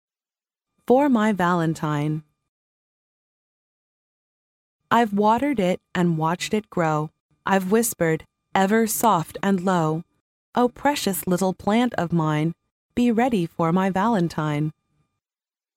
幼儿英语童谣朗读 第16期:给心爱的人 听力文件下载—在线英语听力室